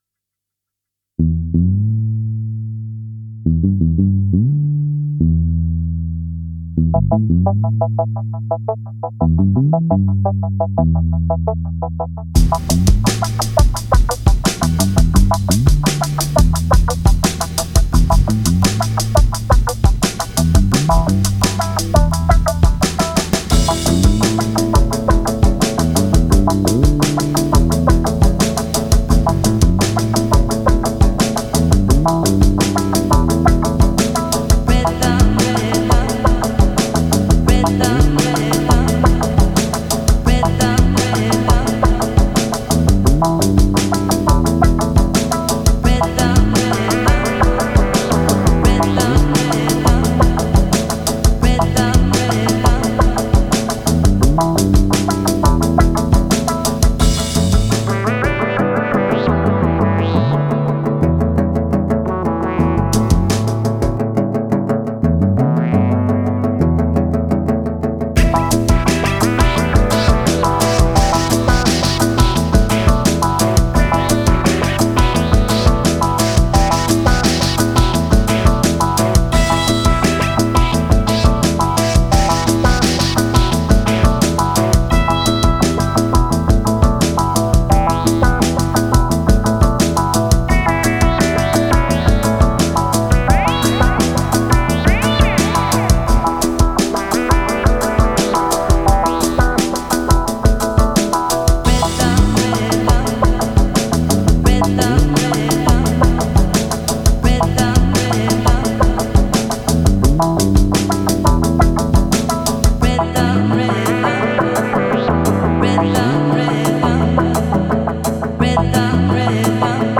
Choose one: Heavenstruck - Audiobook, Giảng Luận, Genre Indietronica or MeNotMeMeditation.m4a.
Genre Indietronica